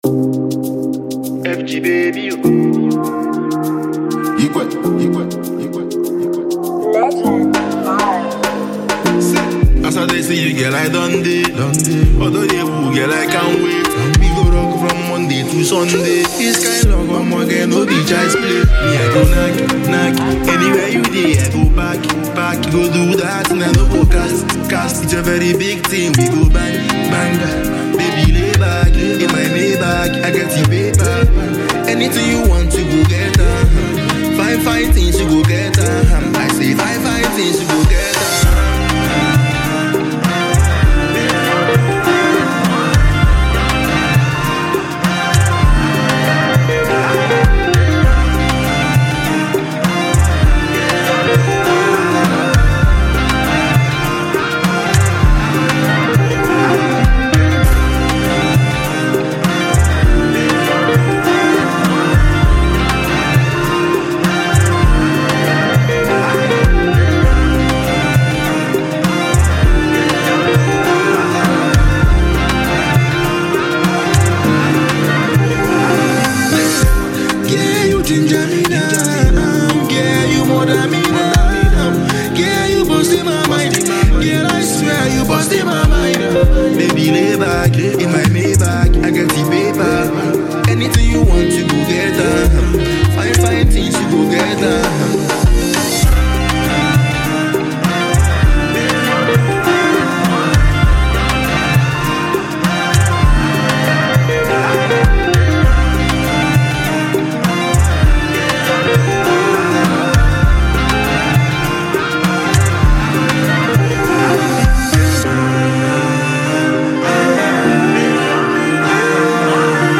soulful melodies with catchy rhythms
smooth production